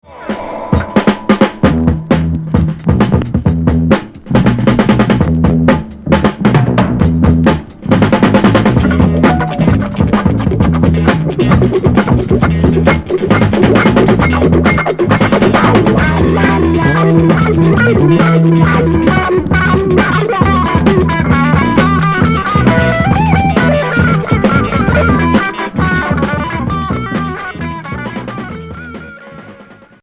Heavy pop psychédélique Quatrième 45t retour à l'accueil